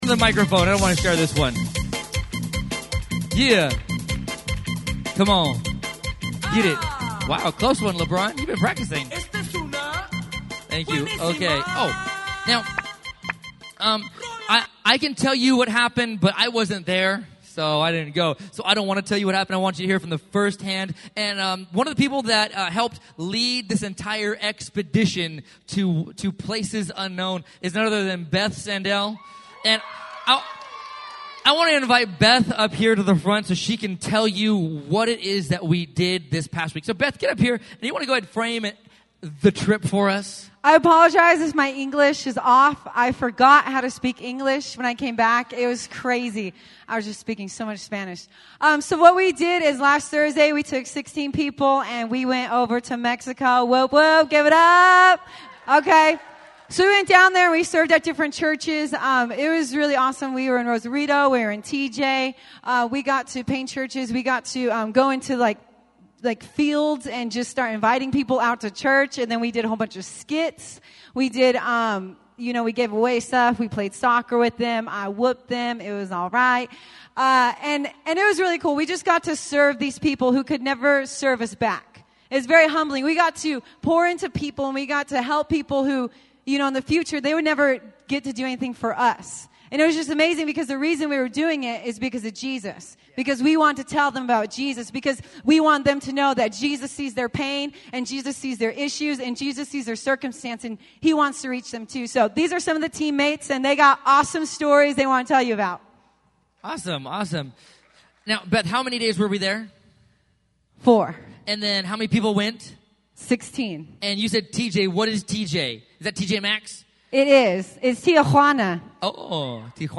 Mission Trip To Mexico Testimonies